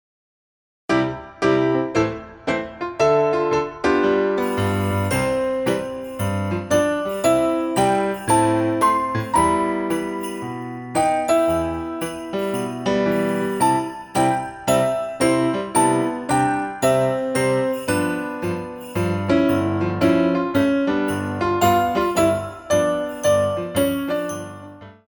Tendu I